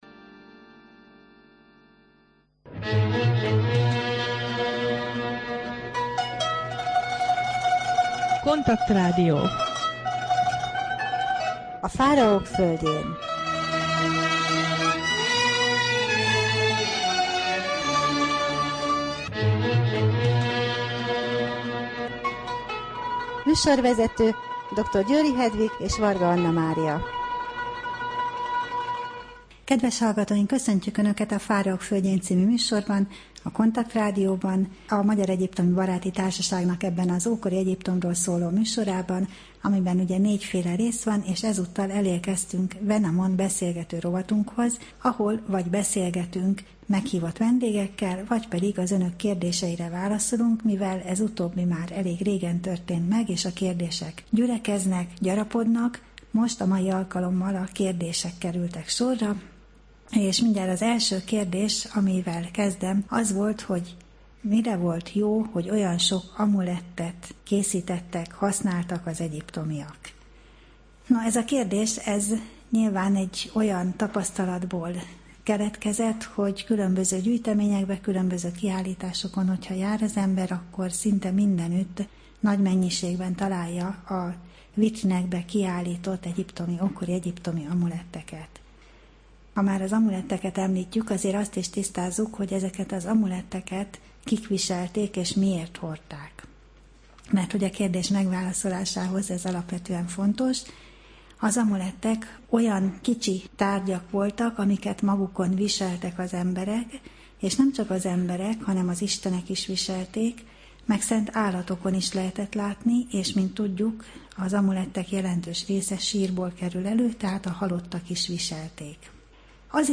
Rádió: Fáraók földjén Adás dátuma: 2012, August 31 Fáraók földjén Wenamon beszélgető rovat / KONTAKT Rádió (87,6 MHz) 2012 augusztus 31. A műsor témája - Kérdések: Mire készítettek és használtak egyszerre olyan sok amulettet az egyiptomiak? Miért értékesek a töredékes papiruszok is? Milyen öltözetkiegészítőket viseltek az ókori egyiptomiak?